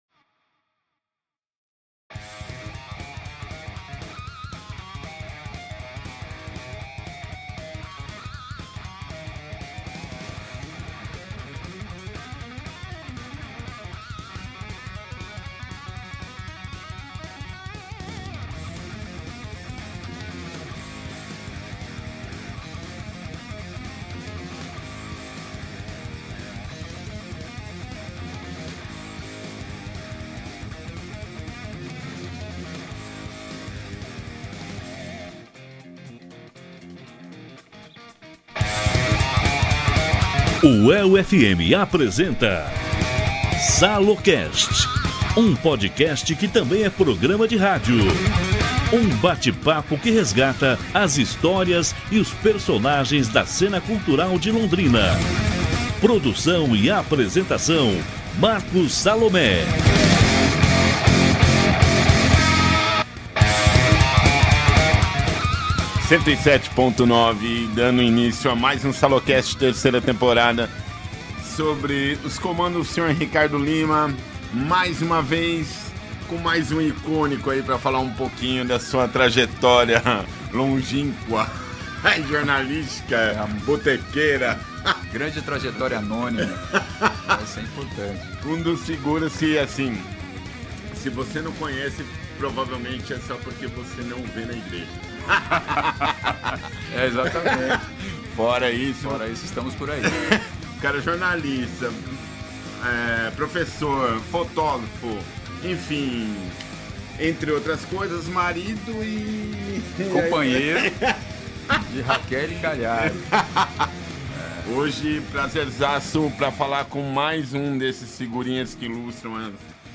Entrevista
Estúdios UEL FM 107.9